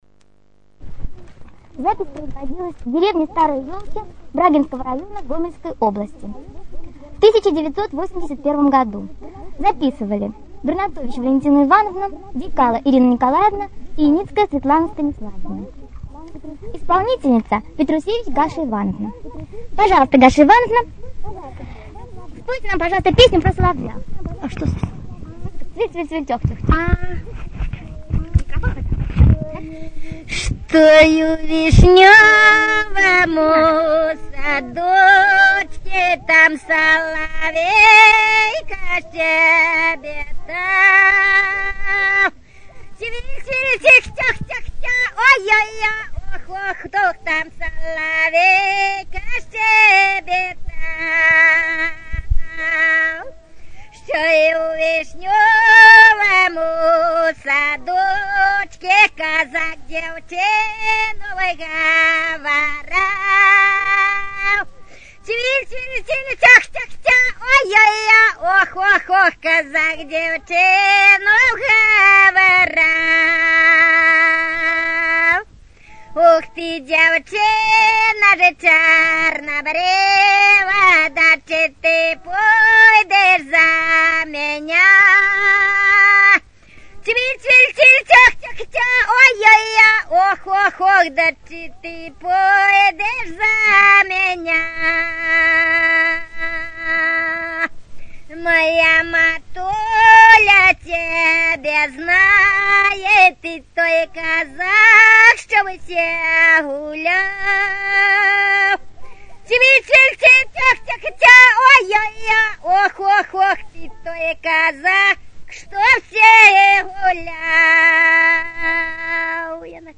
Тема: ЭБ БГУ::Беларускі фальклор::Пазаабрадавая паэзія::любоўныя песні
Месца запісу: в. Старыя елкі